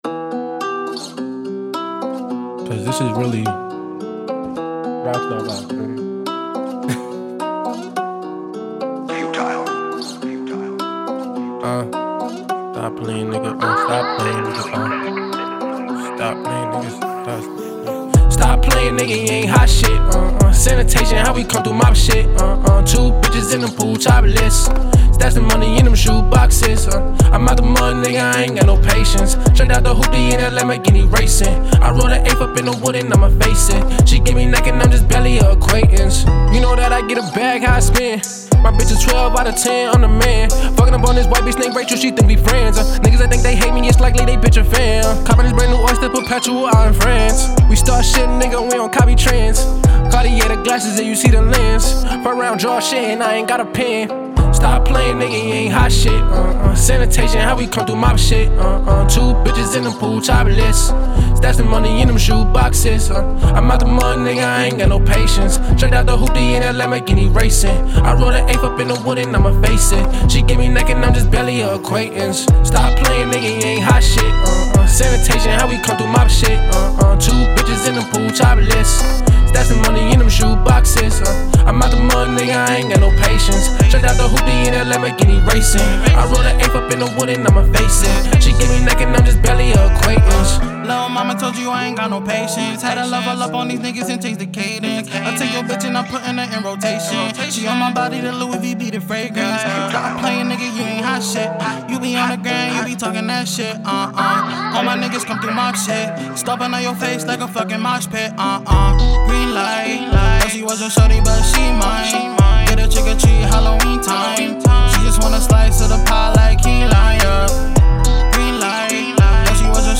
Hiphop
Description : Great vibe good energy party vibe music